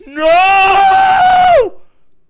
Эти аудио отличаются мягкостью и глубиной, создавая расслабляющую или ностальгическую атмосферу.